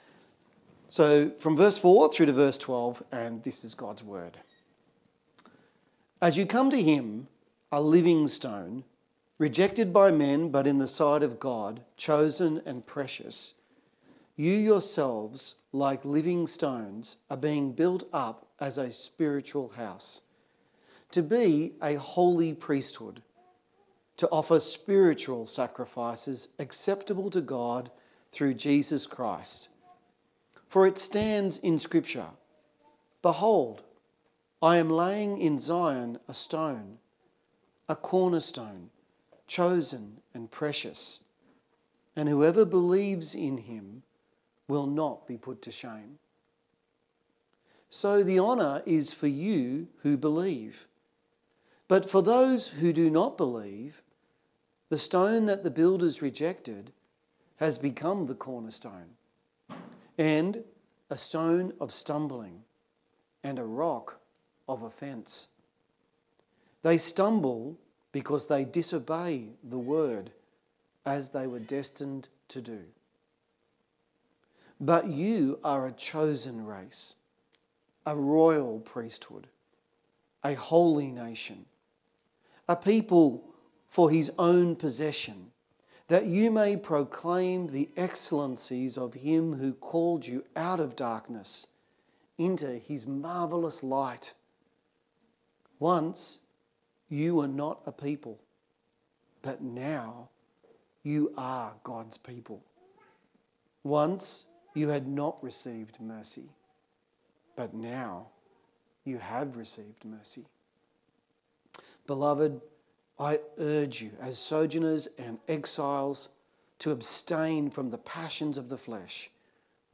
1 Peter 2:4-12 Sermon